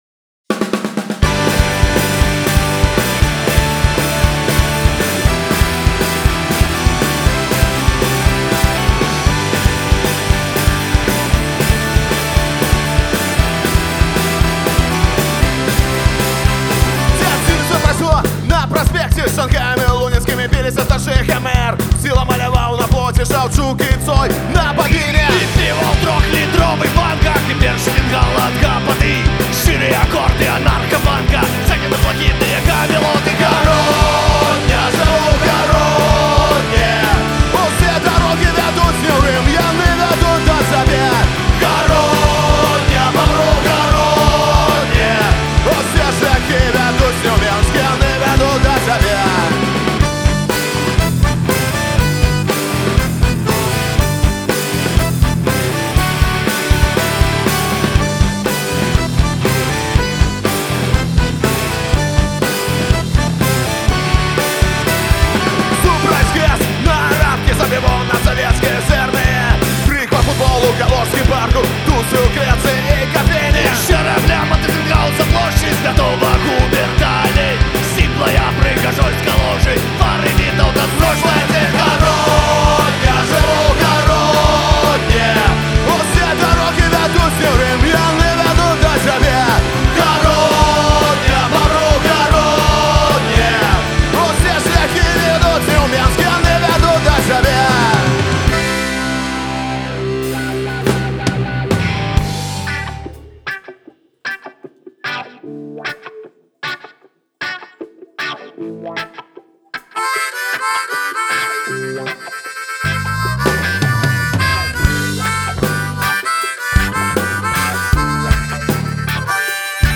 Фольк-панк гурт заснаваны ў 2012 ў Горадні.